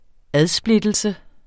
Udtale [ ˈaðˌsbledəlsə ]